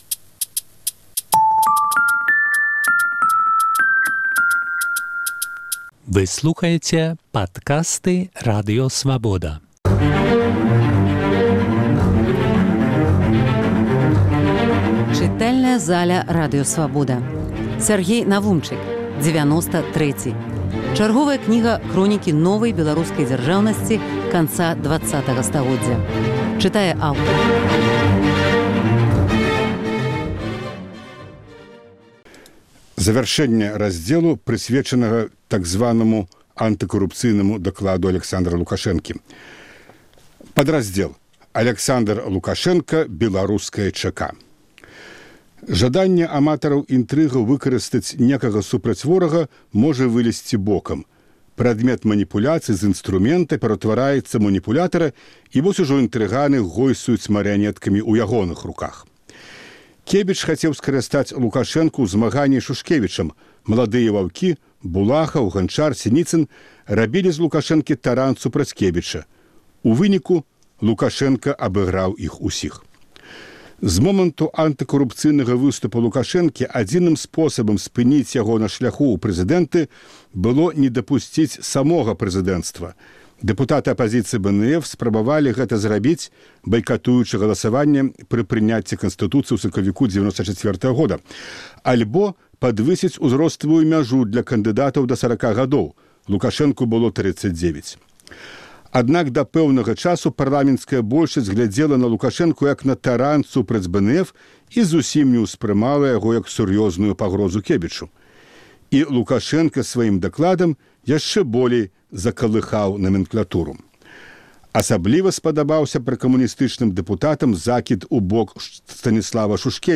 Заключная частка кнігі Сяргея Навумчыка "Дзевяноста трэці". Чытае аўтар